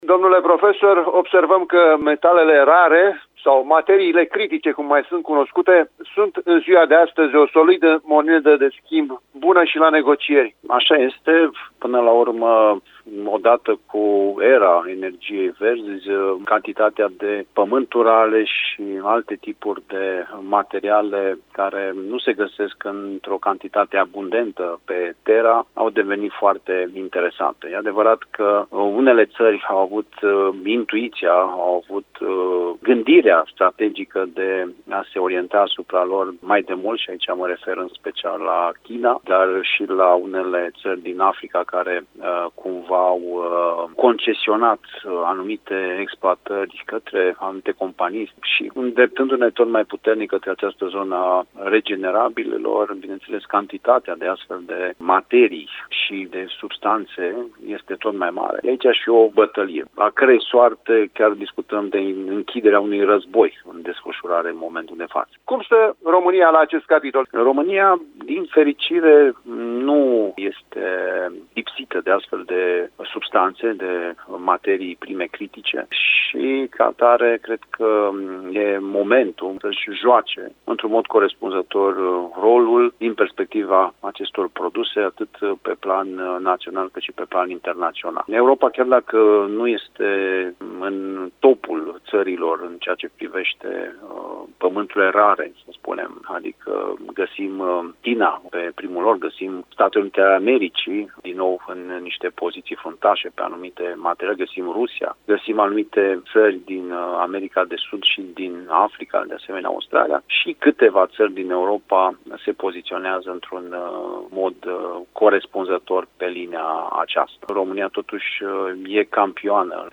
Un dialog pe această temă